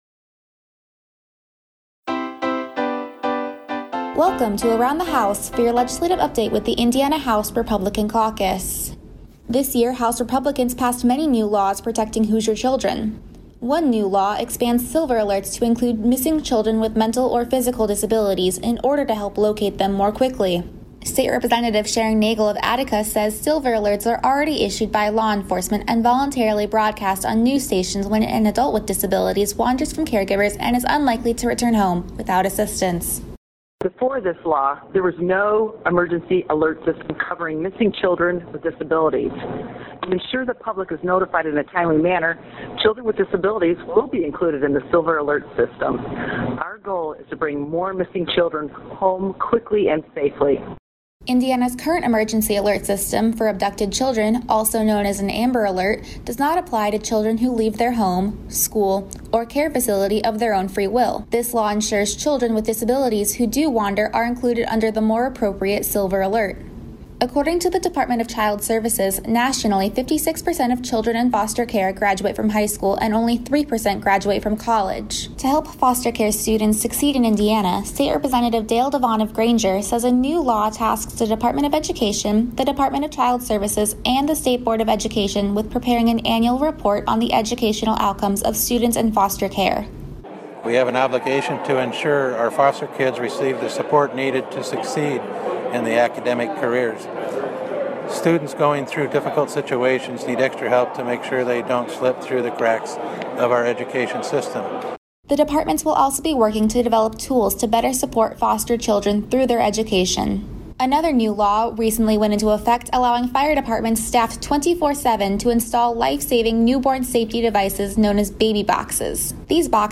State Rep. Sharon Negele (R-Attica) explains a new law helping locate children with disabilities who wander away more quickly.
And State Rep. Bob Behning (R-Indianapolis) says a new law allowing fire departments staffed 24/7 to install newborn safety devices known as baby boxes has already saved a life in his community.